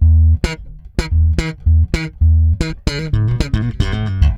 -AL DISCO F.wav